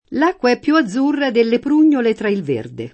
prugnola [pr2n’n’ola; ant. o region. prun’n’0la] s. f. («susina selvatica») — es. con acc. scr.: l’acqua è più azzurra delle prùgnole tra il verde [